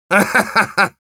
Spy_laughshort01_ru.wav